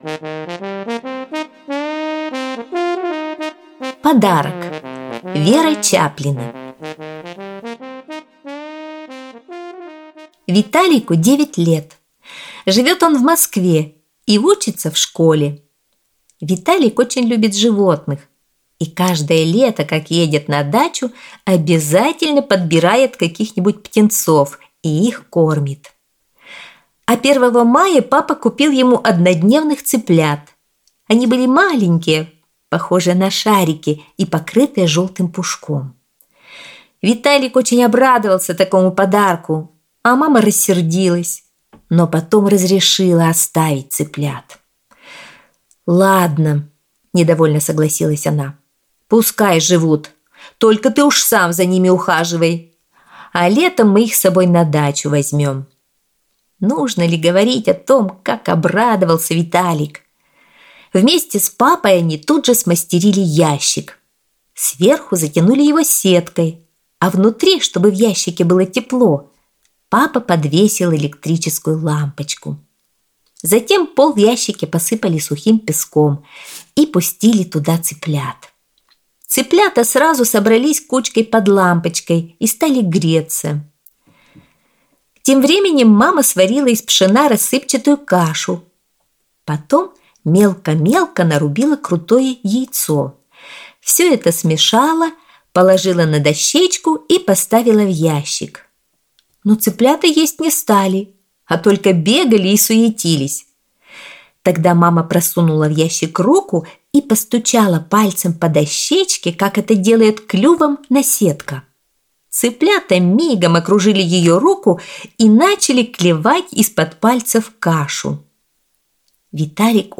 Аудиорассказ «Подарок»